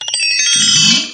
Save.wav